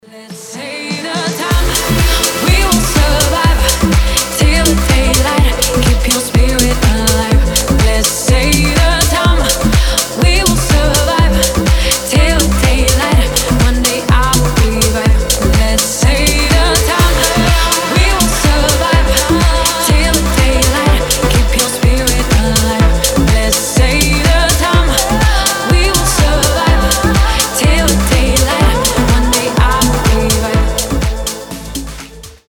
танцевальные , женский голос , dance pop